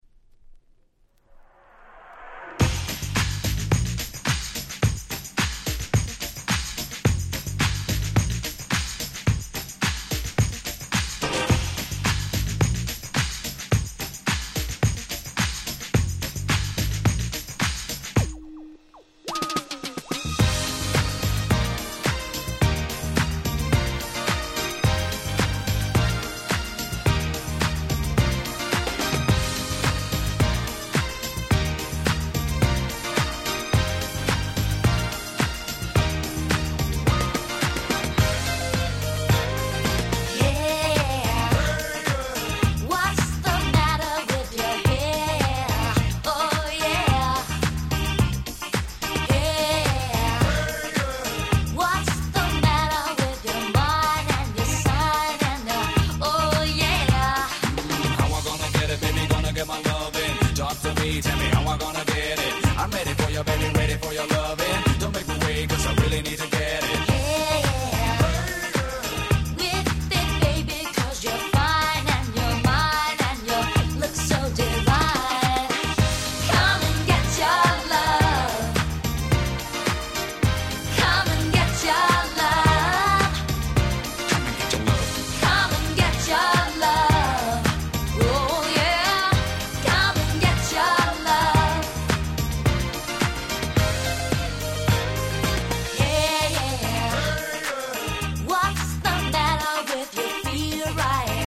キャッチー系R&B Classics !!
この曲はそんなAlbumの中でも一際キャッチーオーラを放ちまくっていた1曲。
程よいRagga風味も相まって大変使い易い1曲です！